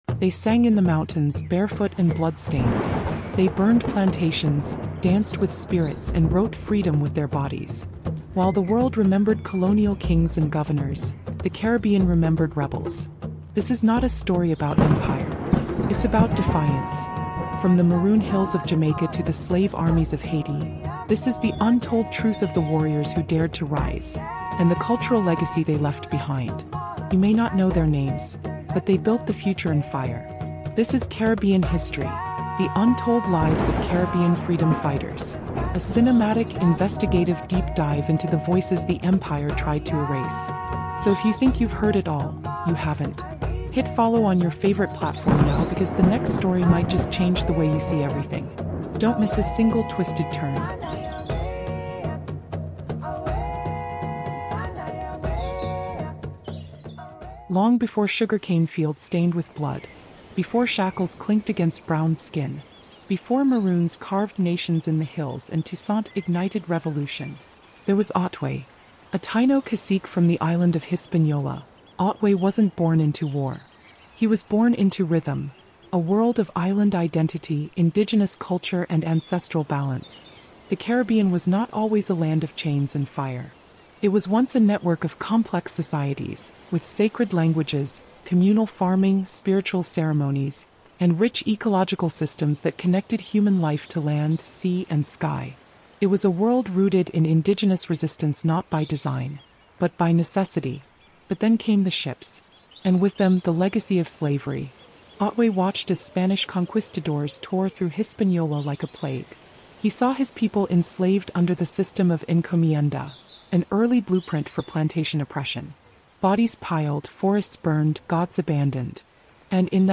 CARIBBEAN HISTORY: The Untold Lives of Caribbean Freedom Fighters is a raw, immersive audiobook documentary exploring the heroes of black heritage, Caribbean identity, and cultural resistance. Through vivid storytelling and deeply researched history stories, this Caribbean podcast brings to life revolutionary legends like Queen Nanny of the Maroons, Toussaint Louverture, Paul Bogle, and Cuffy — icons who shaped the resilience and heritage of the Caribbean diaspora.